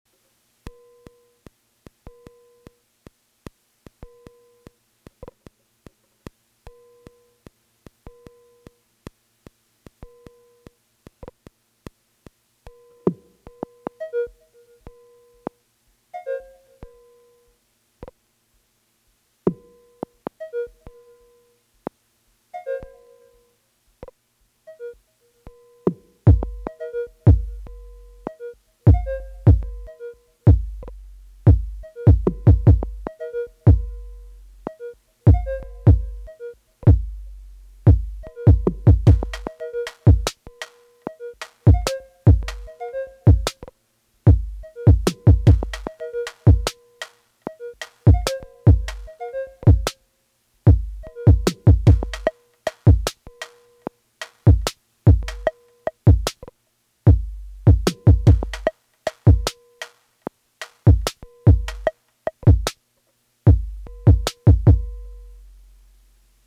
I mentioned this in the ‘vinyl noise’ thread, but this also has some pleasing to my ears benefits from amplifying the noise floor of the ST. The distortion on the FX block must have some kind of built in noise gating because you have to turn it all the way down to get the hiss louder.
I also used the metronome at a really low volume on the intro which I suppose is another species of odd syntakt trick.